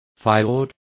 Complete with pronunciation of the translation of fiord.